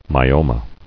[my·o·ma]